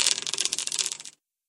PixelPerfectionCE/assets/minecraft/sounds/mob/silverfish/step2.ogg at mc116
step2.ogg